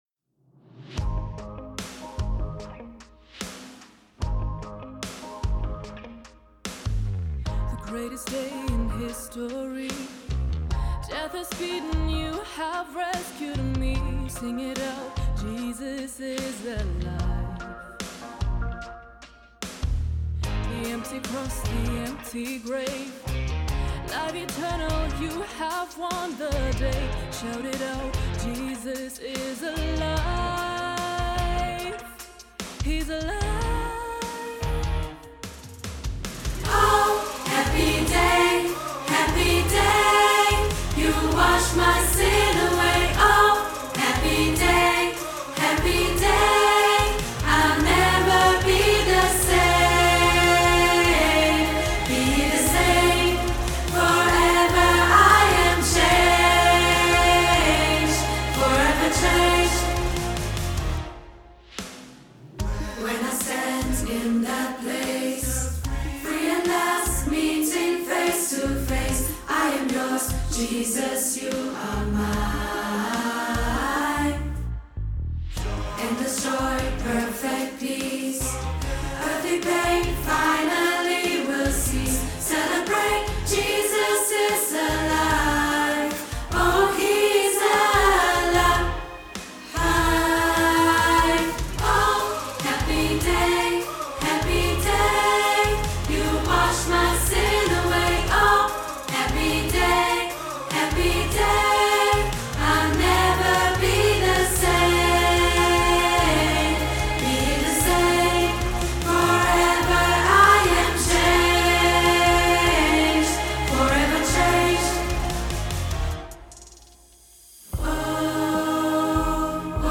Audiospur Alt